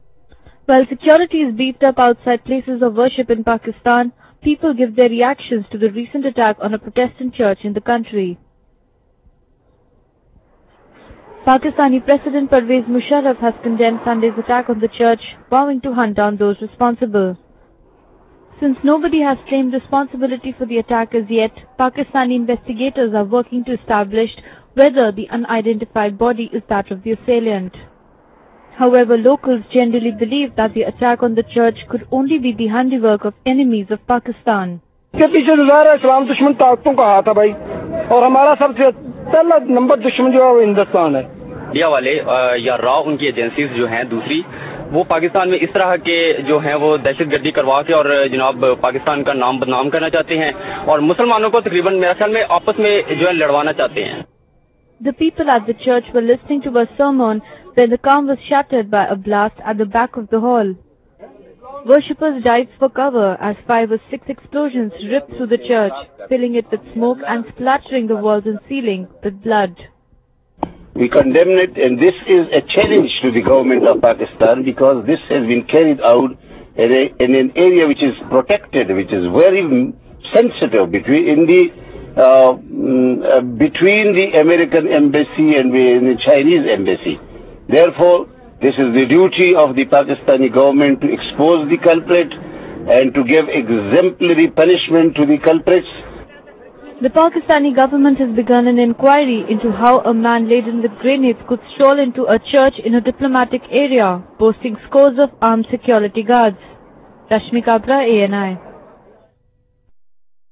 While security is beefed up outside places of worship in Pakistan, people give their reactions to the recent attack on a Protestant church in the country.